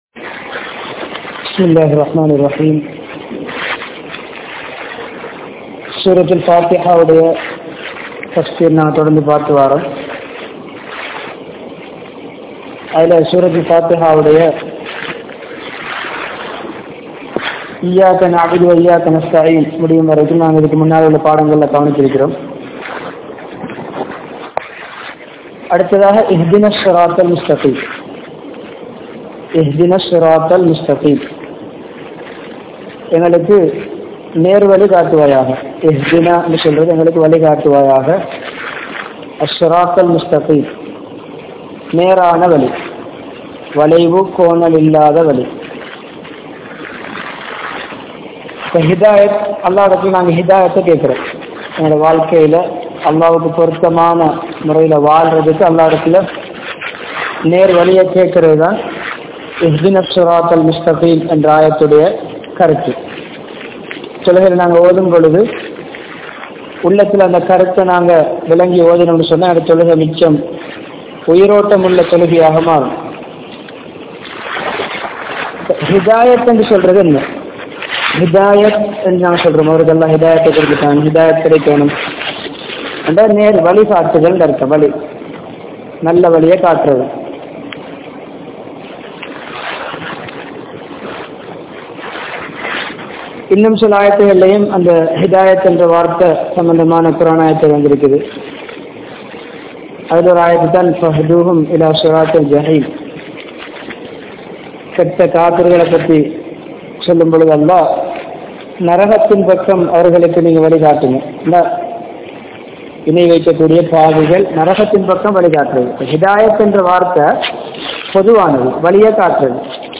Surah Fathiha (Thafseer) | Audio Bayans | All Ceylon Muslim Youth Community | Addalaichenai
Hambantota, Warasamull Jumma Masjidh